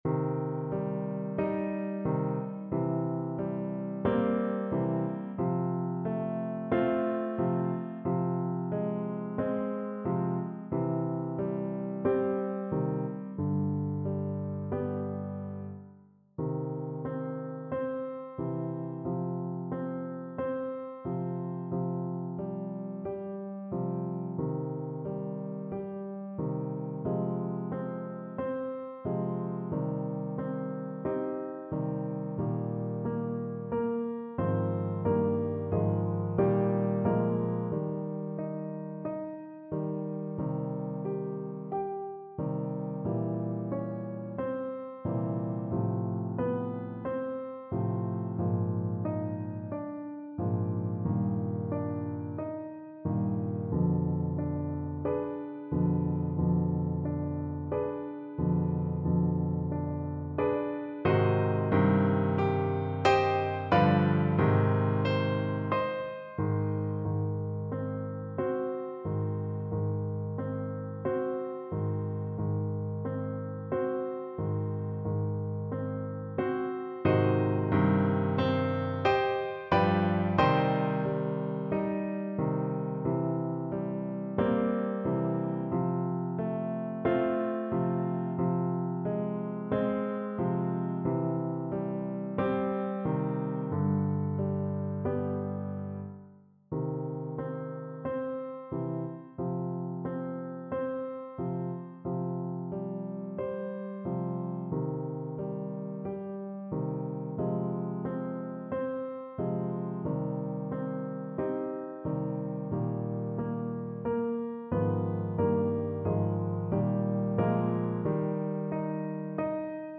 Classical
Piano Playalong MP3